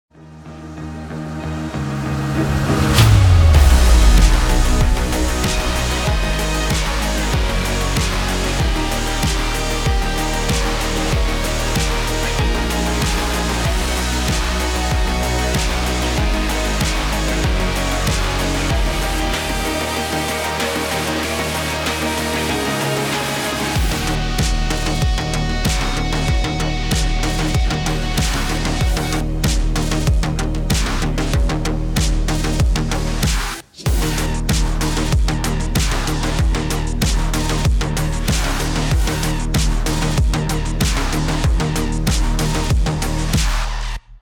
Champions 2021 Finisher 2 sound effects